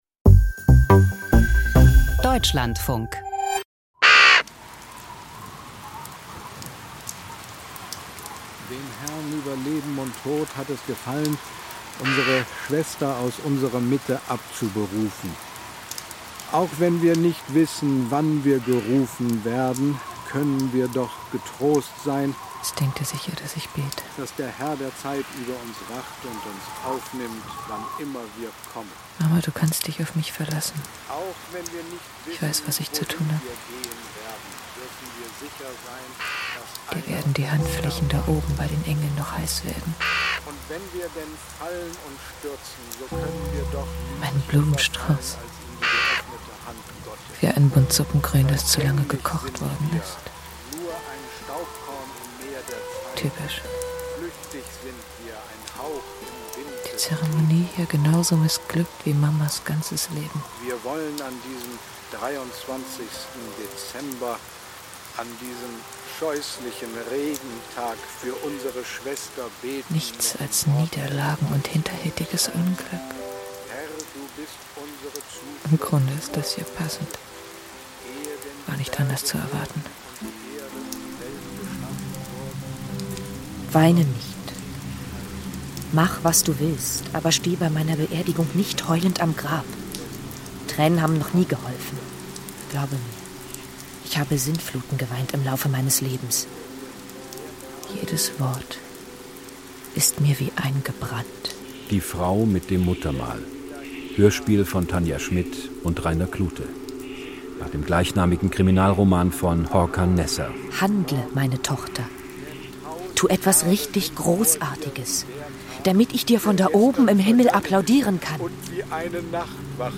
Aus dem Podcast Kriminalhörspiel Podcast abonnieren Podcast hören Podcast Krimi Hörspiel Die ganze Welt des Krimis in einem Podcast: Von Agatha Christie bis Donna Leon und Kommissar...